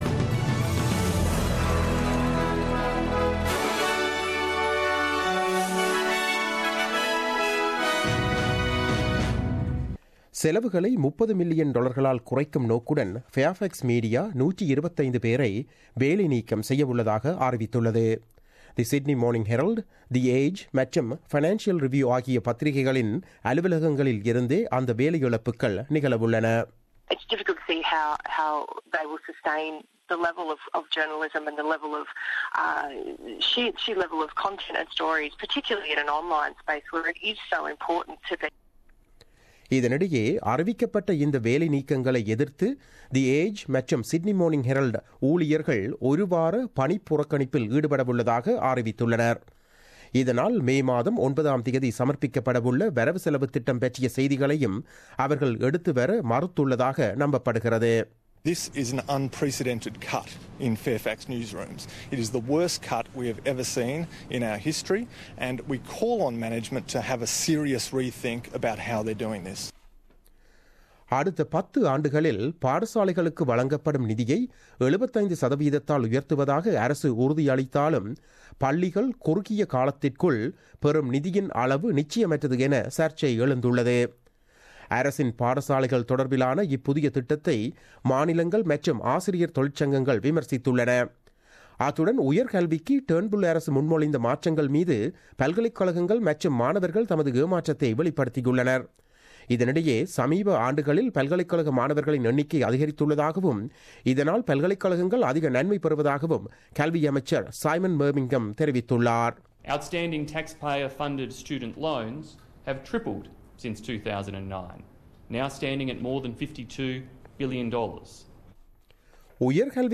The news bulletin aired on Wednesday 03 May 2017 at 8pm.